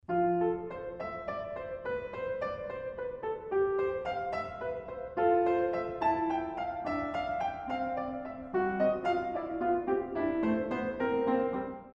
in F Mojor